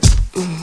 drumm.wav